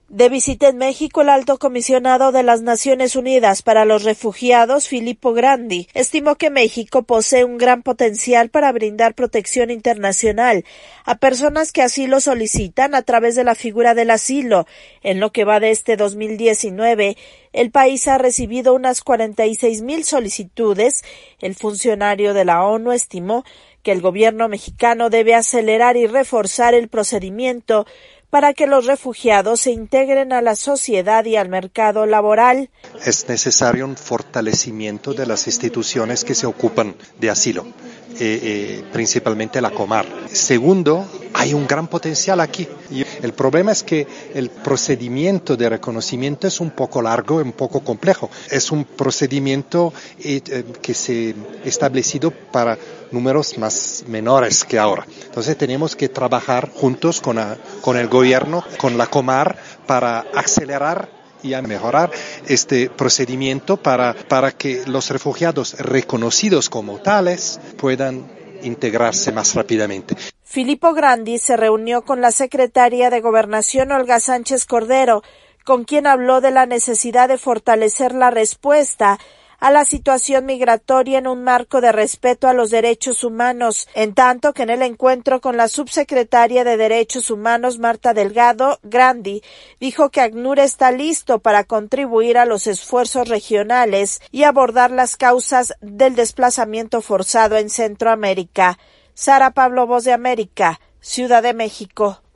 VOA: INFORME DESDE MEXICO